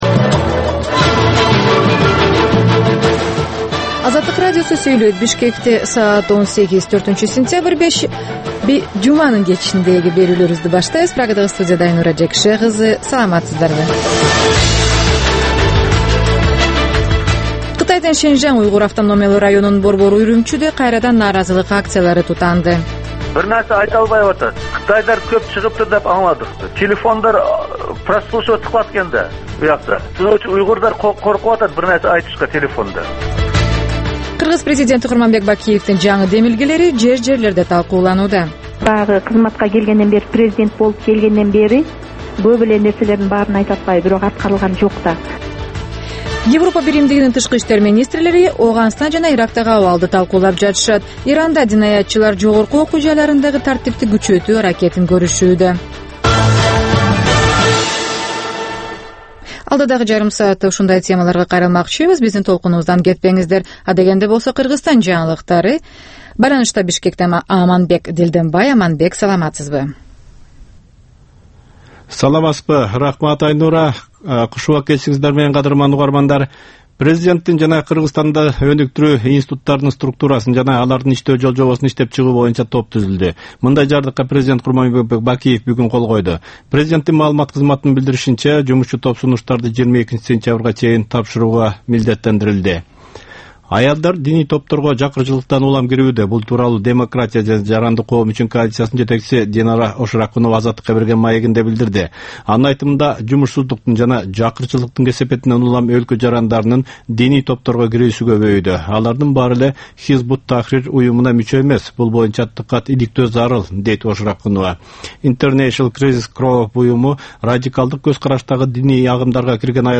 "Азаттык үналгысынын" бул кечки алгачкы берүүсү жергиликтүү жана эл аралык кабарлардан, репортаж, маек, баян жана башка берүүлөрдөн турат. Бул үналгы берүү ар күнү Бишкек убактысы боюнча саат 18:00ден 18:30га чейин обого түз чыгат.